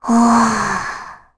Rehartna-Vox_Sigh3_kr.wav